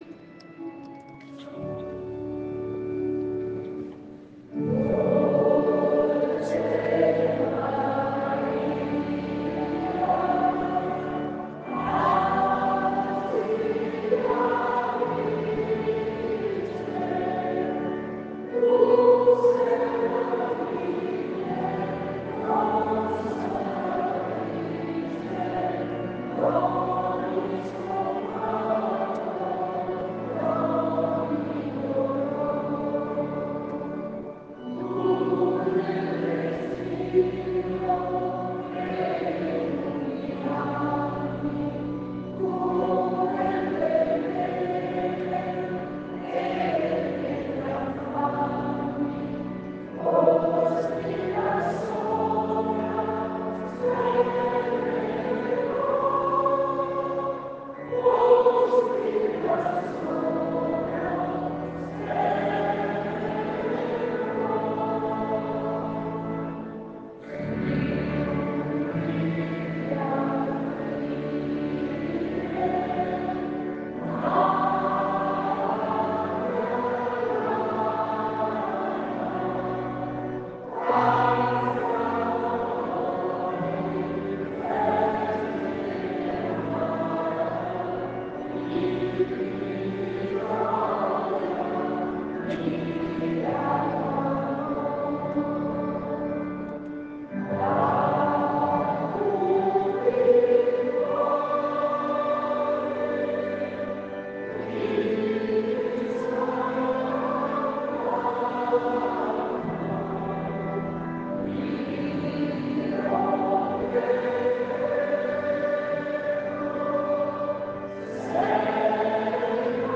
scusa la scarsa qualità di questi .mp3